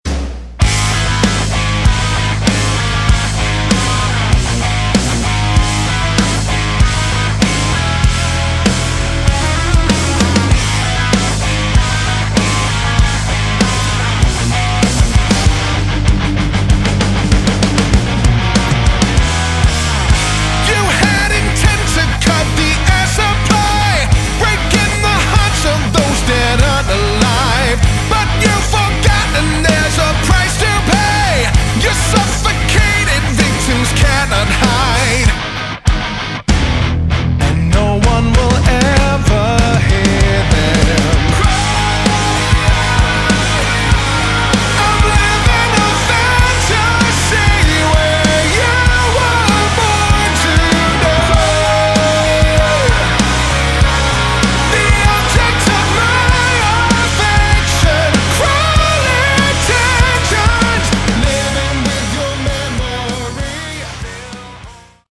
Category: Hard Rock
Lead Vocals
Guitar
Bass Guitar
Drums, Percussion
keys, piano